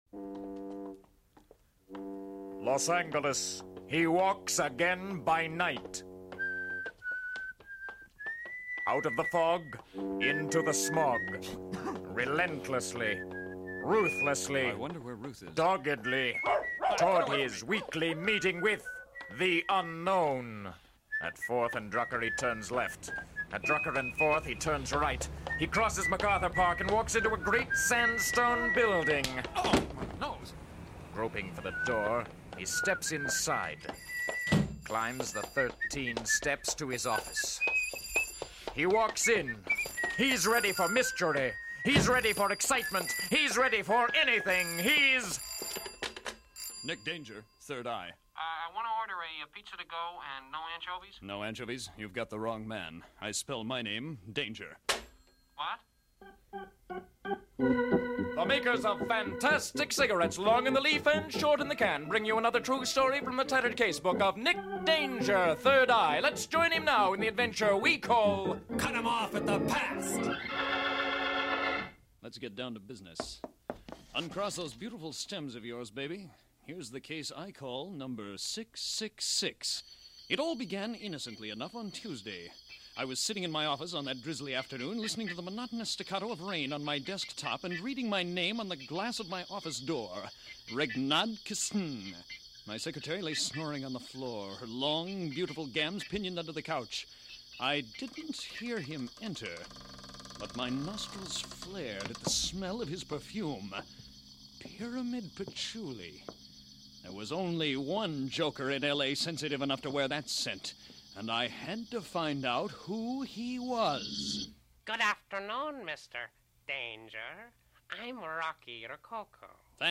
Radio Theatre: The Further Adventures of Nick Danger